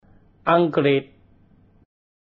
What speaks for the "angles" is the fact that the "k" in "ankrit" does not really sound like a "k" in English, closer to a "g".